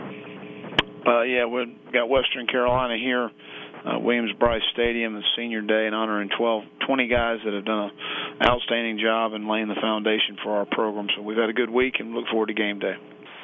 shared his thoughts with the media on the game against Western Carolina.